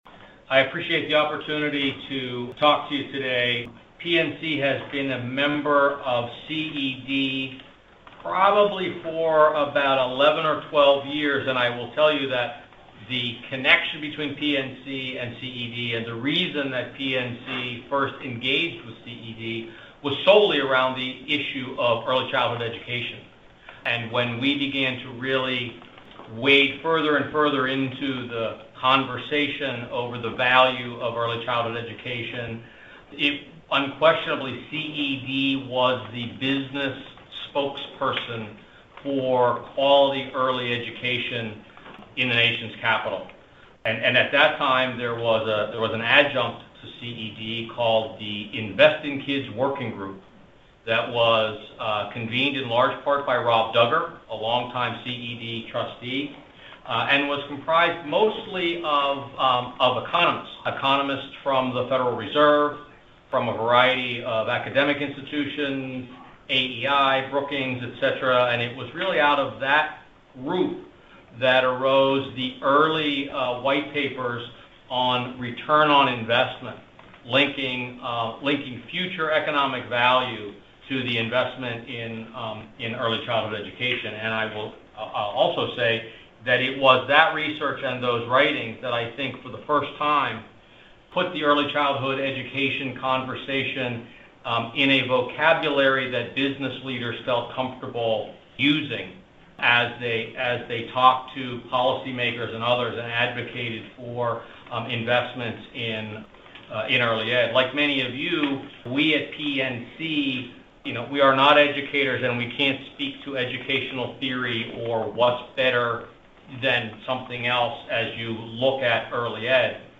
Remarks